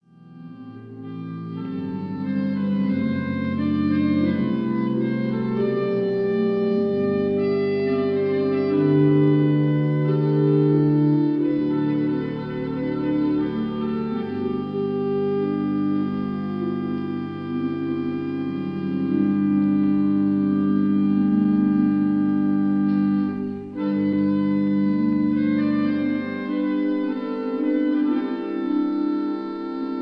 Organ of Église Sainte-Aurélie
Strasbourg